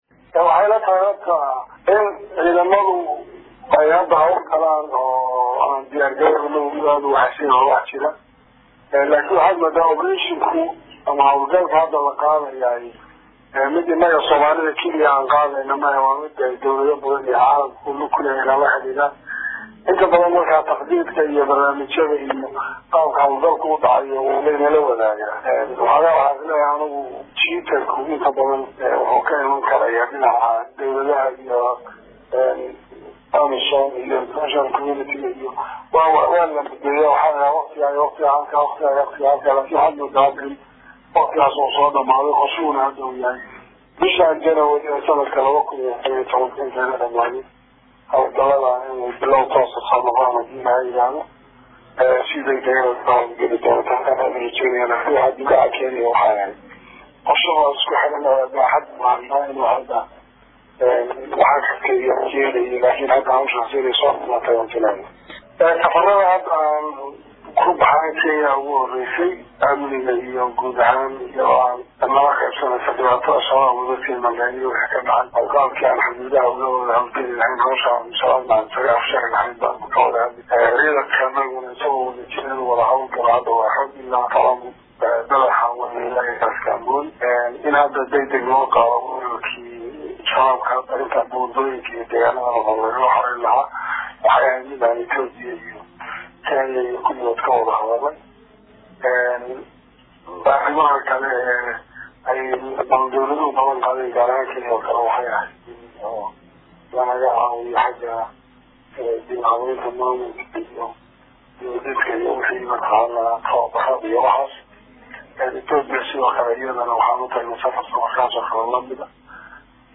Halkan Ka Dhageyso Codka Hogaamiyha Maamulka Jubba.